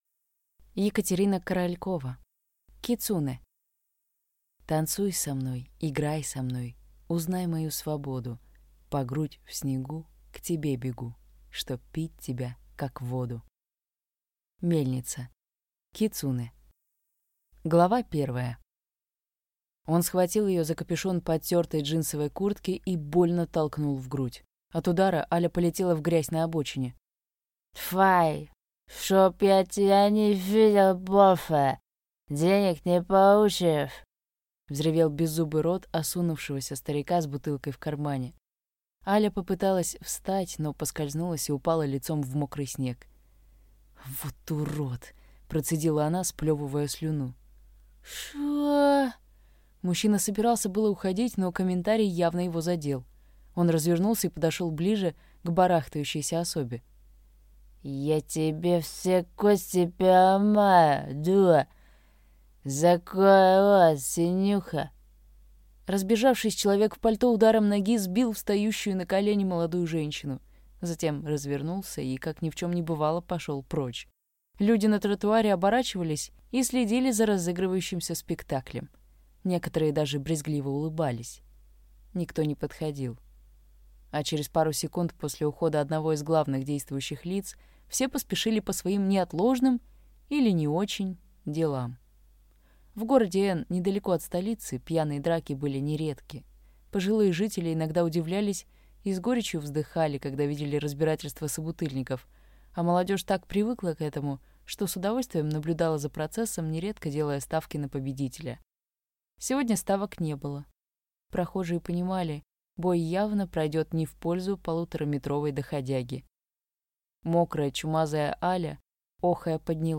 Аудиокнига Кицунэ | Библиотека аудиокниг